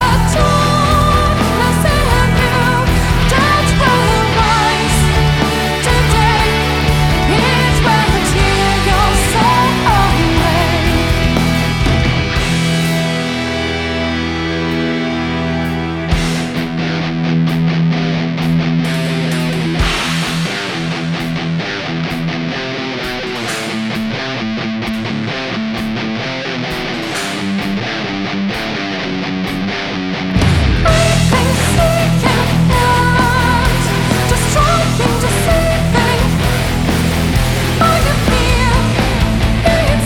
Guitars
Keyboard
Drums